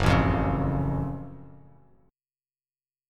GbmM7 chord